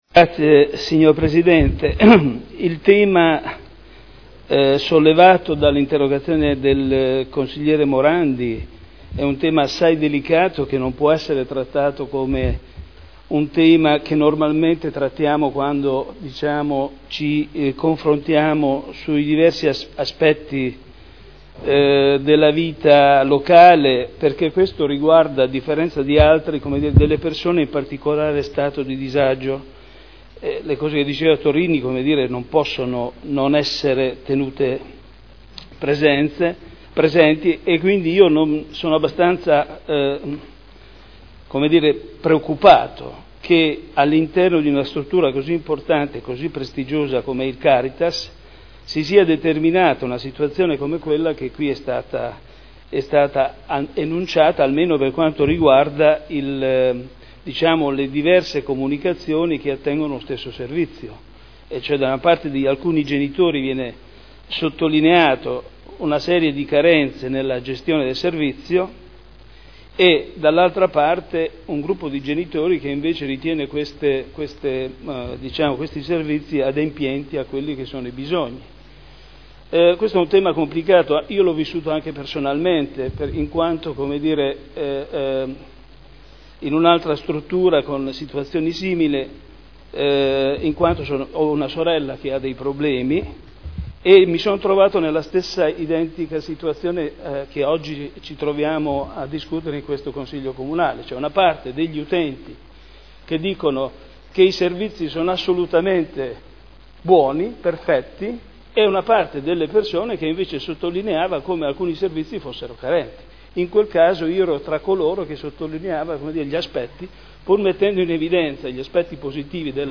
Michele Andreana — Sito Audio Consiglio Comunale